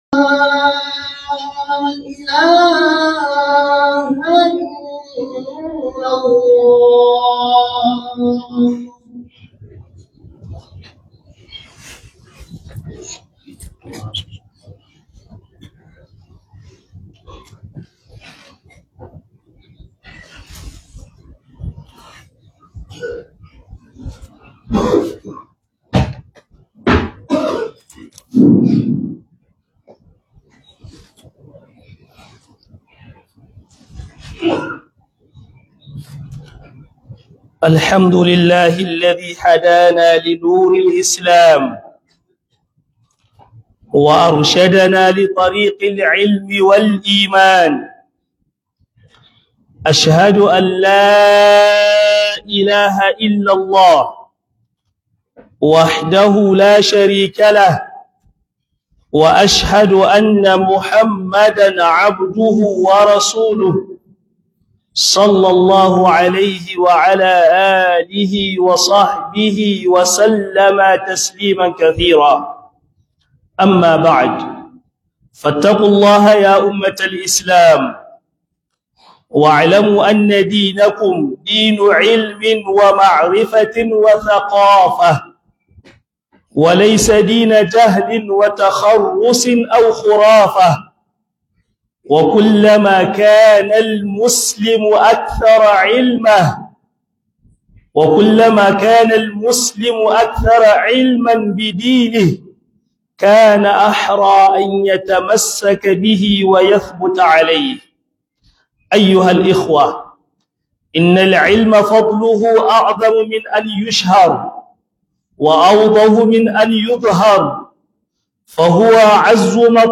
Mahimmanci Ilimi a Rayuwa - HUDUBA